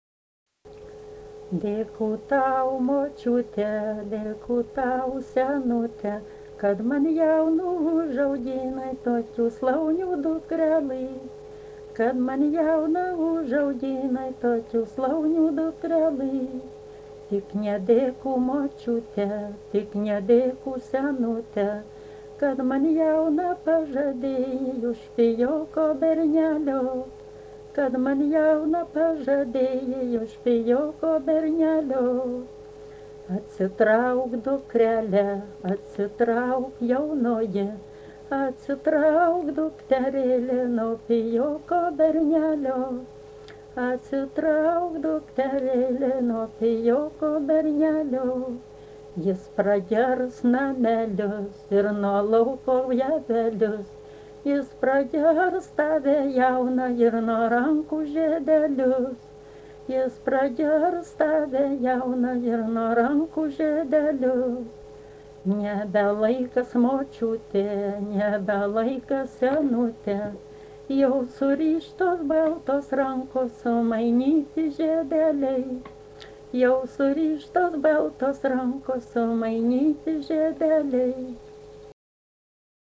daina, vestuvių
Čypėnai
vokalinis